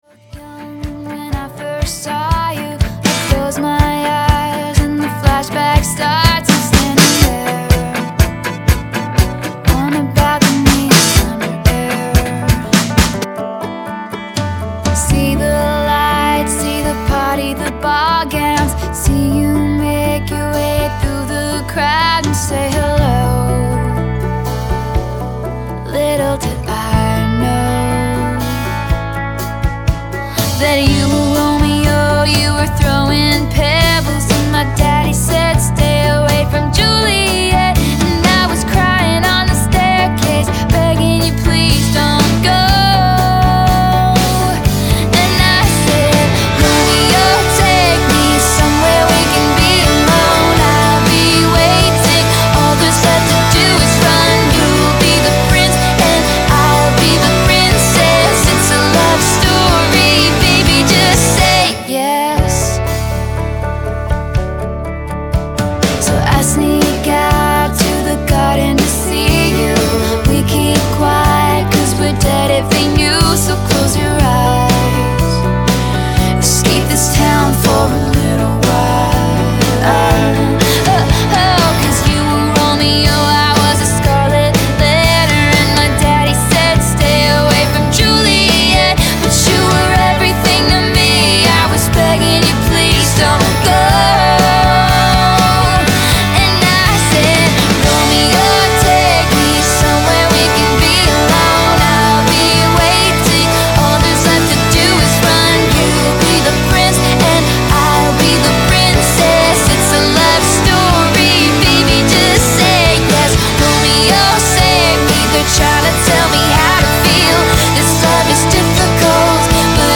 A live hip-hop and pop mix